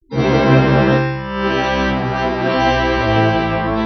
Classical sc02 (part missing 50ms) .wav reconstructed using temporal AR interpolation